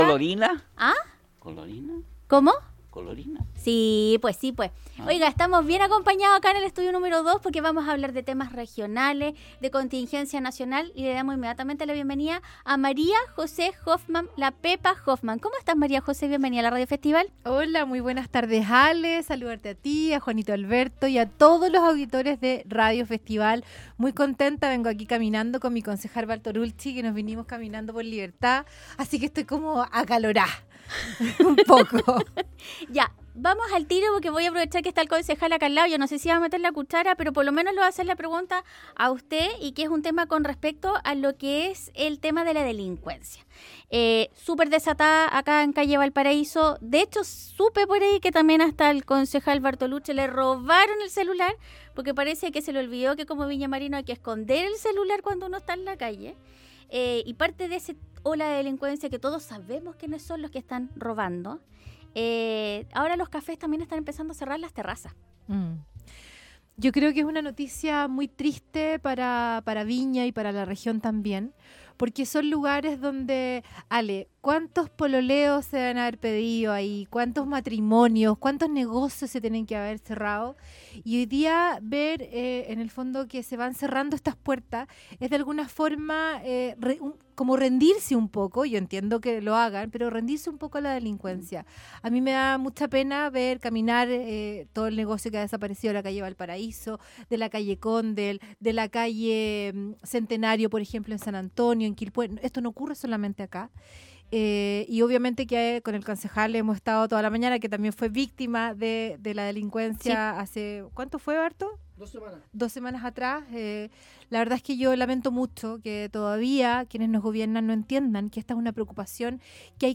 La candidata al senado por la Región de Valparaíso, conversó con Radio Festival sobre la cuenta pública, la delincuencia desatada en Viña del Mar y el lanzamiento de la campaña de Evelyn Matthei